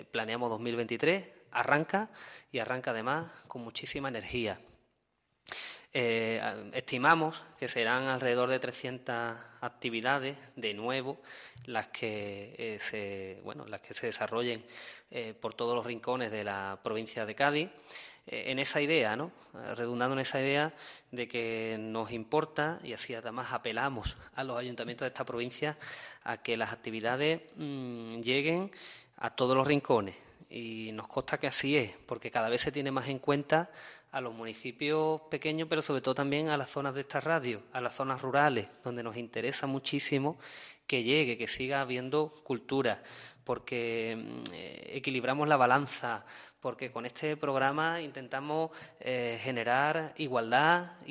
El diputado Antonio González Mellado ha presentado las novedades de este programa que se desarrollará entre abril y noviembre